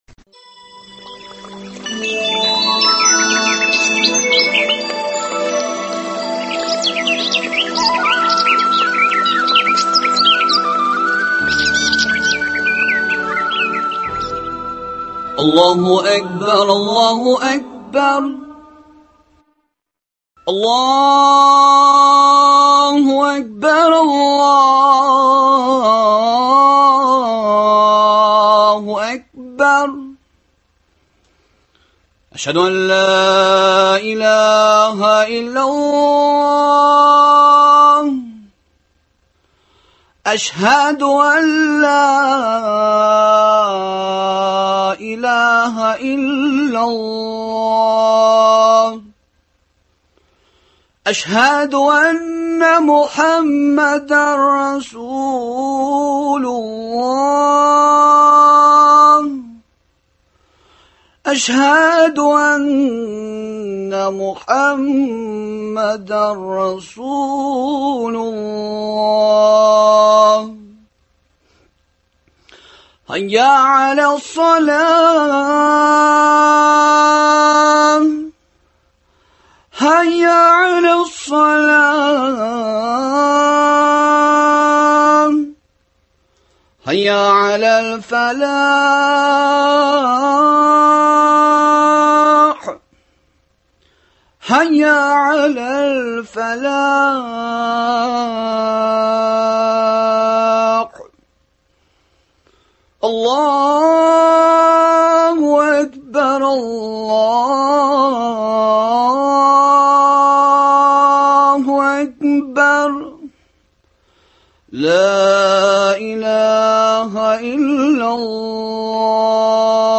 әңгәмәләр циклы